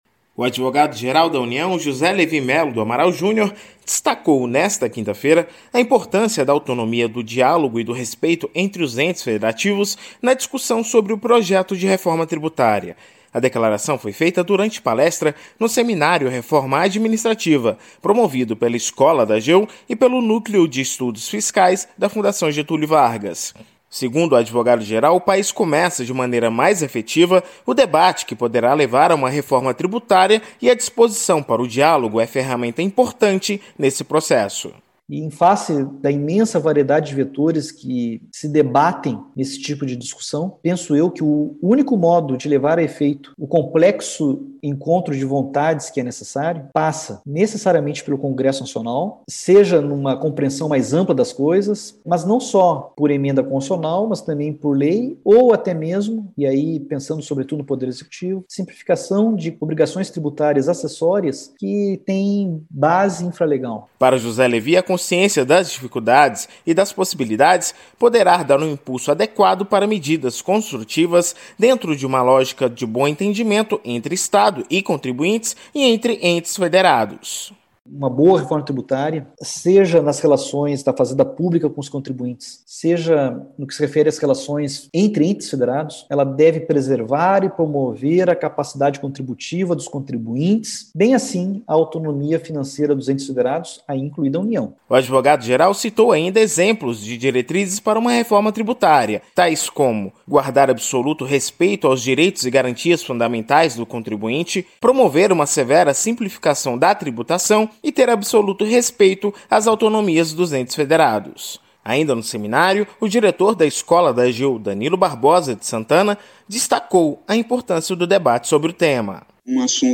Ministro foi palestrante em Seminário promovido pela Escola da AGU